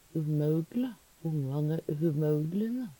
au-lyden kan for nokre vere nærare øu